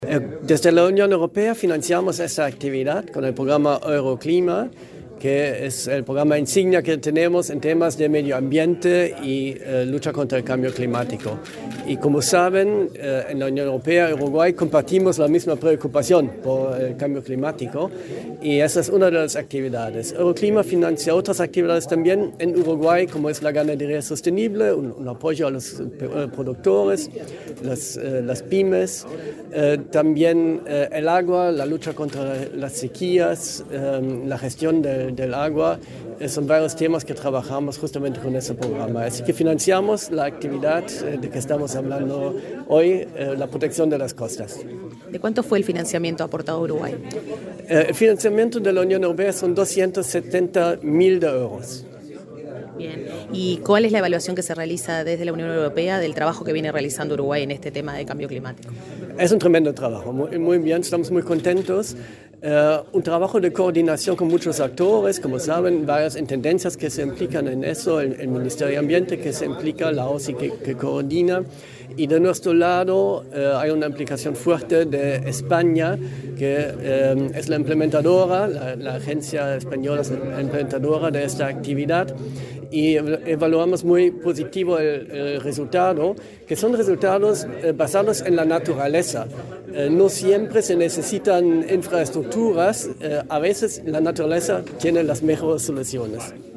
Entrevista al encargado de negocios de la delegación de la Unión Europea, Markus Handke
Entrevista al encargado de negocios de la delegación de la Unión Europea, Markus Handke 10/07/2024 Compartir Facebook X Copiar enlace WhatsApp LinkedIn El encargado de negocios de la delegación de la Unión Europea, Markus Handke , dialogó con Comunicación Presidencial en Torre Ejecutiva, antes de la presentación de resultados sobre avances en la implementación de medidas de adaptación al cambio climático en la zona costera uruguaya.